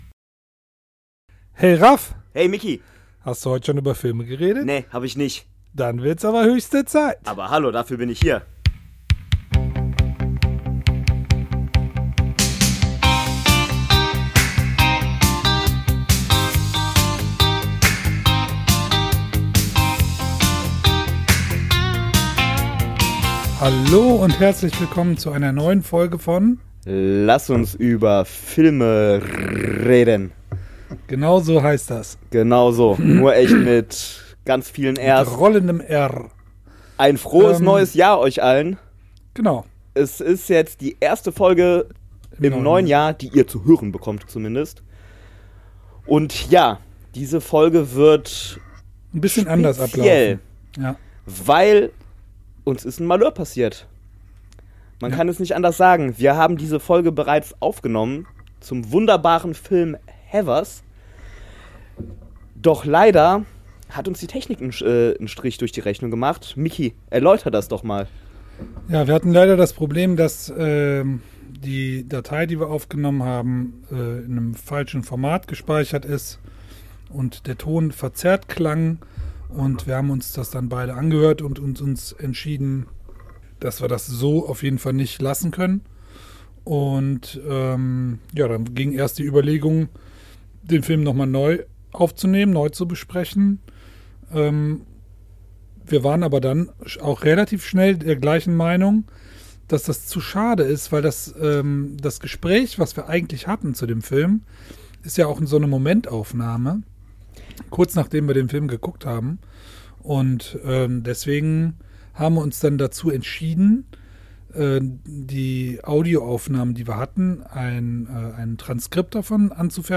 Wir begrüßen Euch im neuen Jahr mit einer etwas....äh, anderen Episode: Da die Originalaufnahme vom Sound absolut unbrauchbar war, haben wir uns entschieden von der Folge ein Transkript erstellen zu lassen und dass einfach mal vorzulesen, und was sollen wir sagen: hoffentlich müssen wir so etwas nie wieder tun;) Mehr